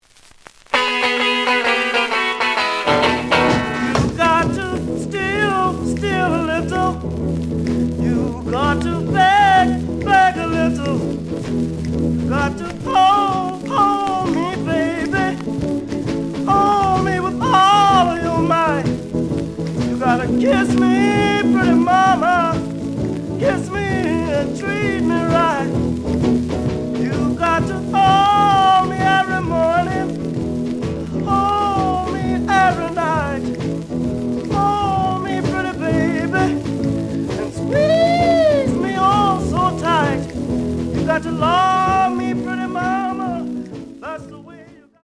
The audio sample is recorded from the actual item.
●Genre: Rhythm And Blues / Rock 'n' Roll
Looks good, but some noise on both sides.)